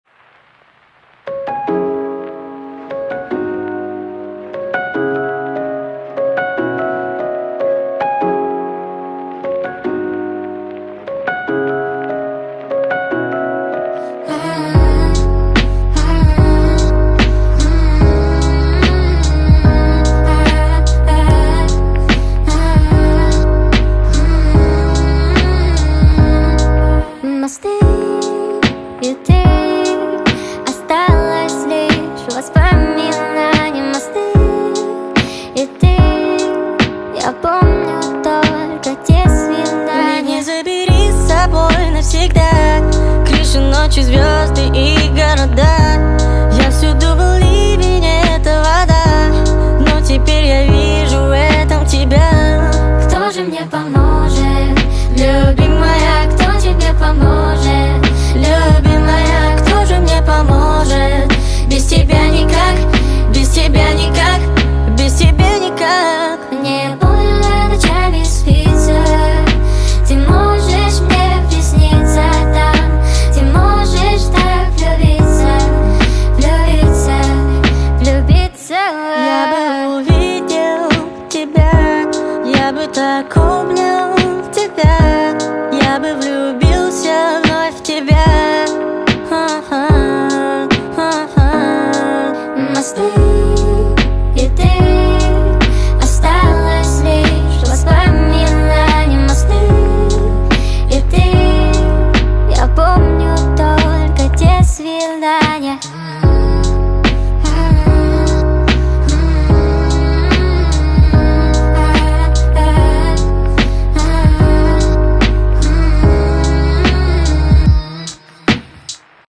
speed up